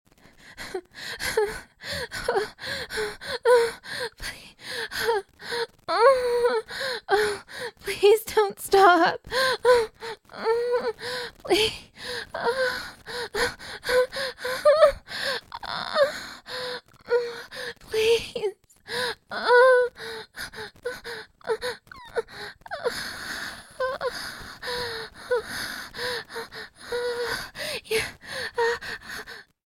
I originally had it twice as long as this, but my microphone is DIED and I don’t have enough money to get a new one x.x I’ve been trying to fix it all day as I had a lot of asks I was excited to do 😦